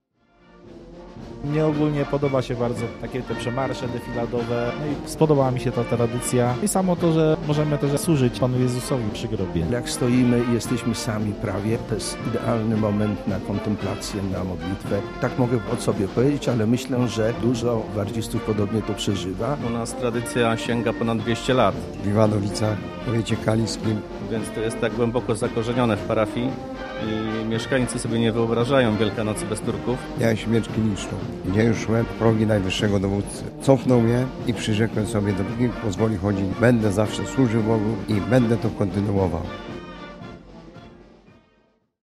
Jak mówią przedstawiciele drużyn z Jeleniej Góry i powiatu kaliskiego: